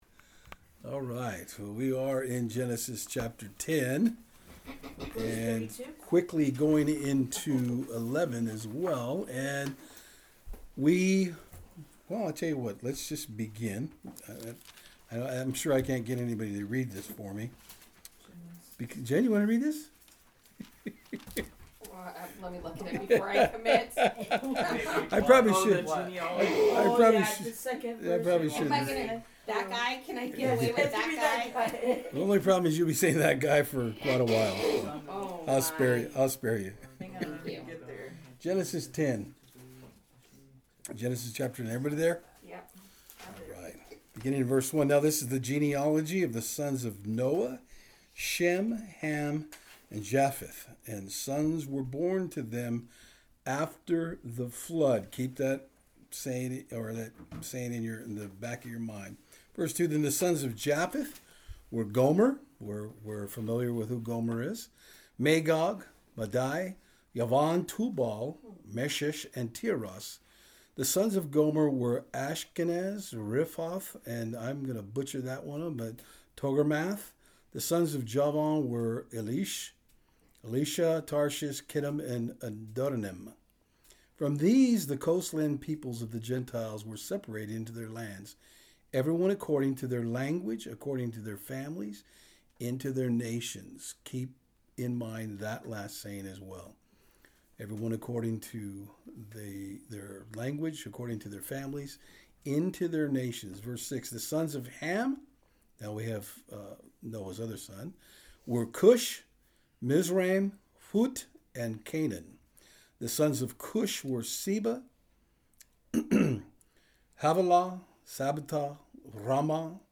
This study is quite different in that in order to complete the lineage of The sons of Noah, it was left open to any questions that the participants had to clarify the text. There is quite a bit of discussion between myself and the group. Also the audio has only been slightly edited form its original format.